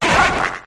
sirfetchd_ambient.ogg